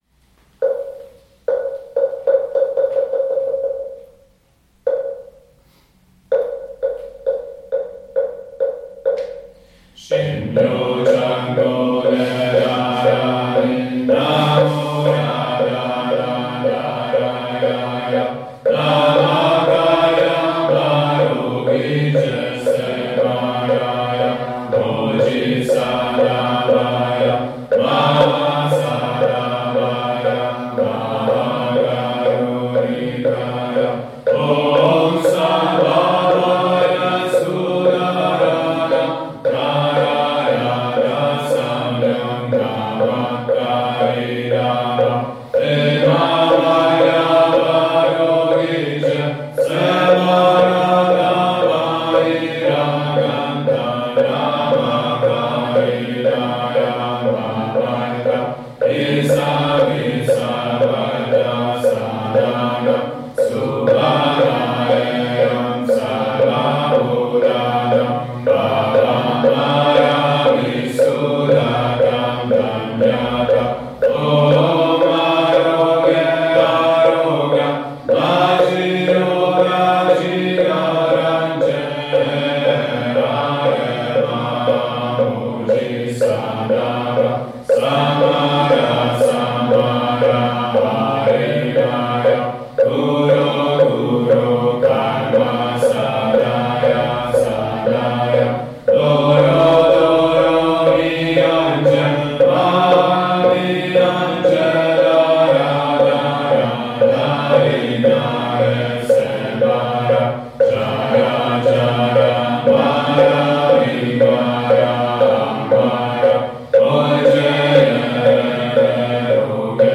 Letöltések - Meditációs énekek
Az alábbi linkekkel letöltheted énekeskönyvünket és a rendszeres gyakorlatunk során recitált énekeket: